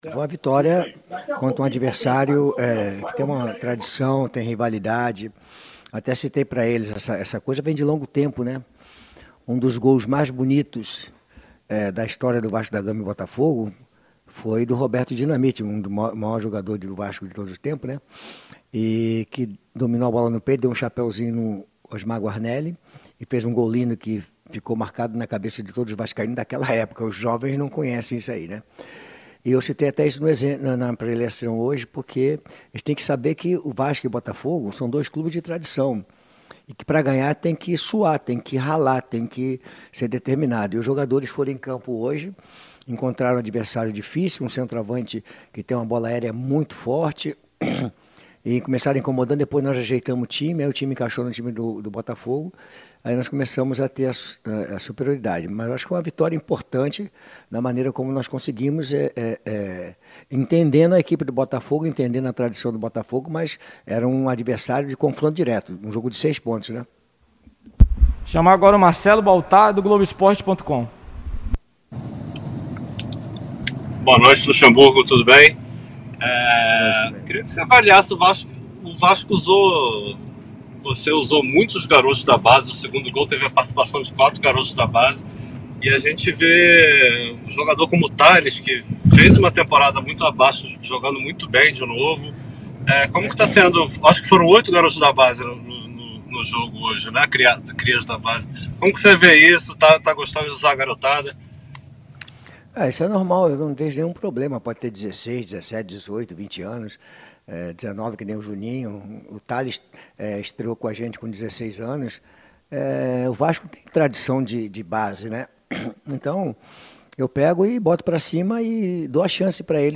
Na entrevista coletiva o técnico Vanderlei Luxemburgo lembrou do gol de Roberto Dinamite, com lençol em cima de Osmar Guarnelli, para realçar a vitória do Vasco, clássico de muita rivalidade e importância no cenário nacional.